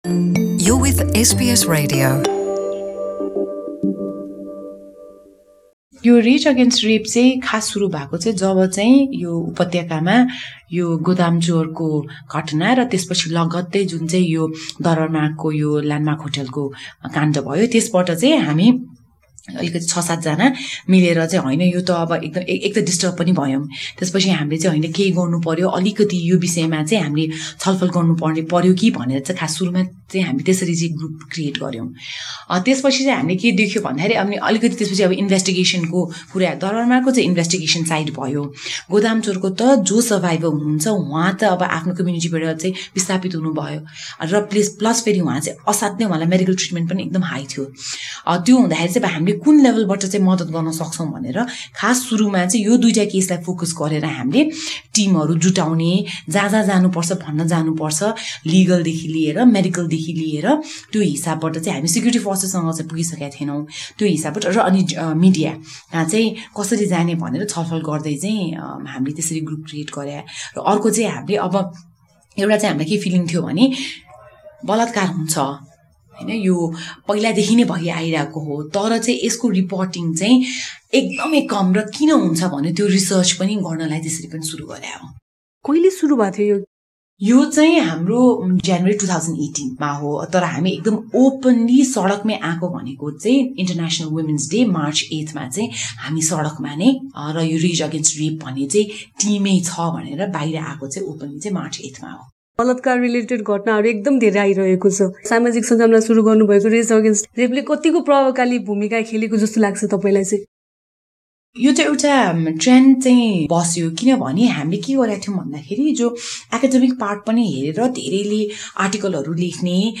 अभियानबारे एसबीएस नेपालीले गरेको कुराकानी